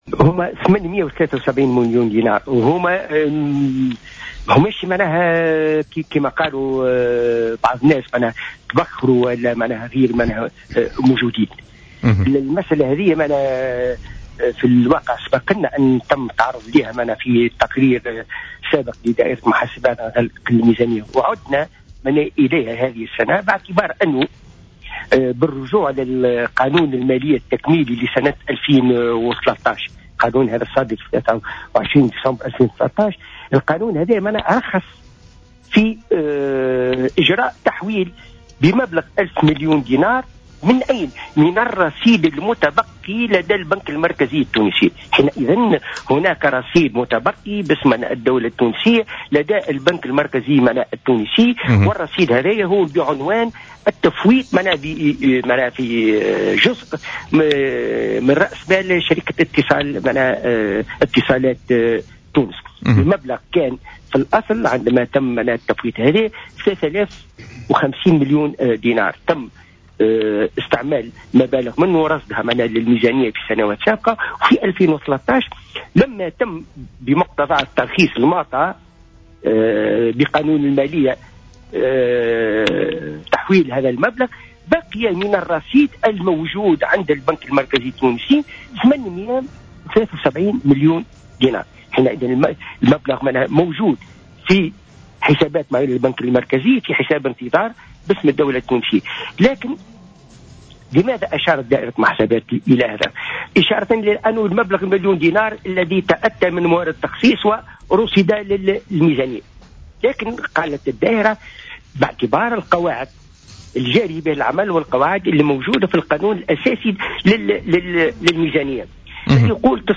أكد رئيس دائرة المحاسبات، عبد اللطيف الخراط في مداخلة له اليوم في برنامج "بوليتيكا" أن مبلغ 873 مليون دينار لم يتبخّر من ميزانية الدولة خلافا ما تم ترويجه في بعض وسائل الإعلام وأنها موجودة في حساب البنك المركزي.